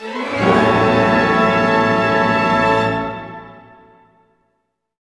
Index of /90_sSampleCDs/Roland - String Master Series/ORC_Orch Gliss/ORC_Major Gliss